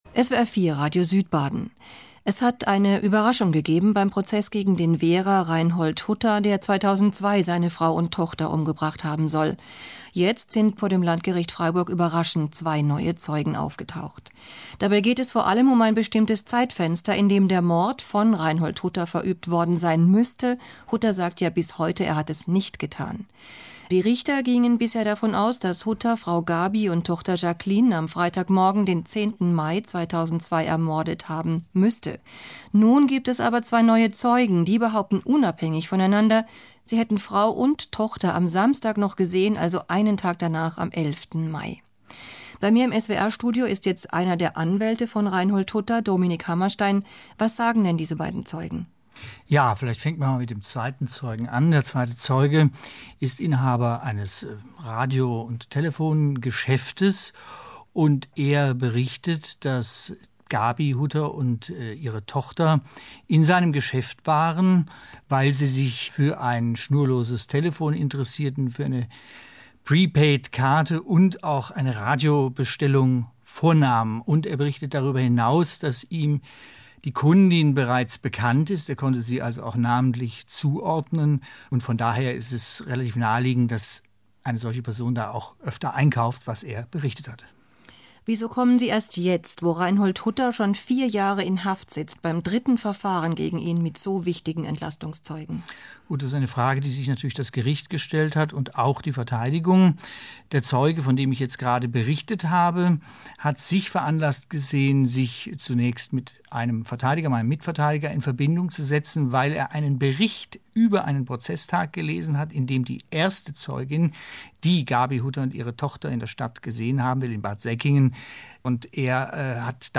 SF 4 Interview vom 03.07.2009; Radio Regenbogen Ausschnitte aus einem Interview vom 21.09.2009 zum Thema Zivilcourage, Ausschnitt 1, Ausschnitt 2, Ausschnitt 3 ).